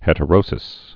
(hĕtə-rōsĭs)